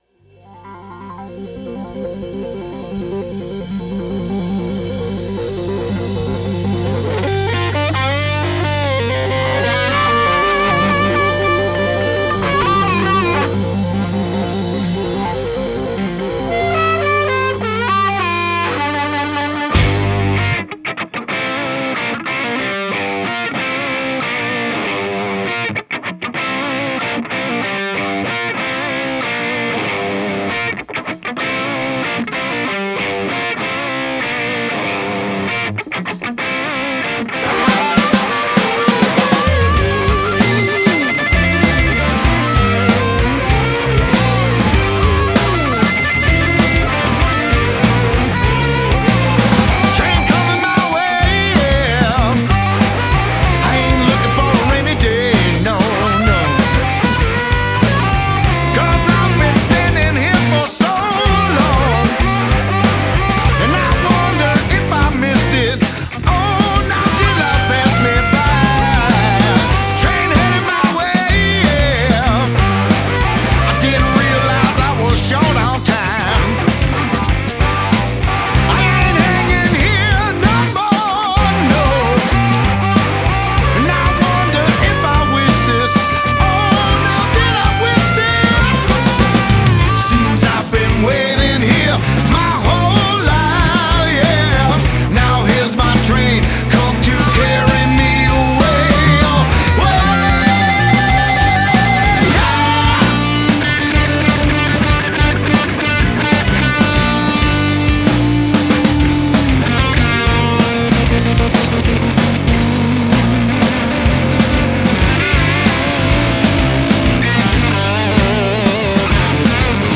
Rock
губной гармошкой